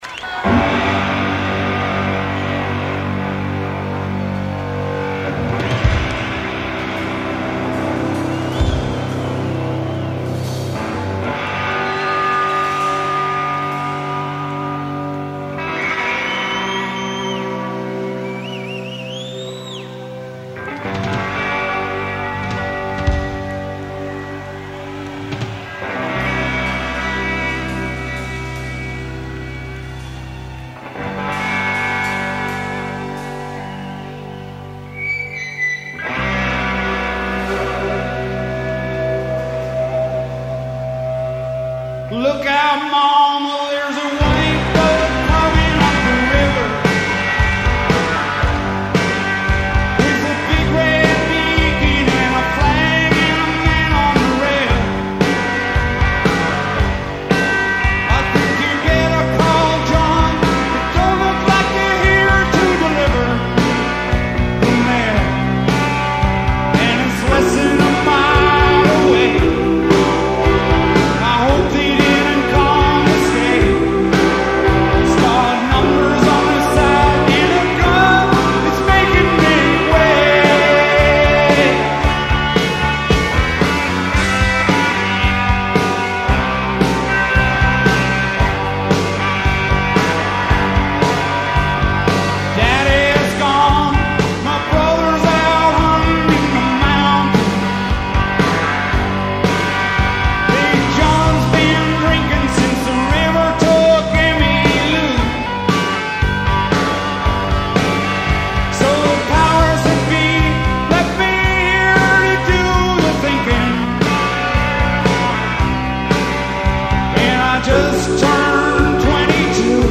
Here they are from the Cow Palace in 1986.